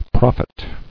[prof·it]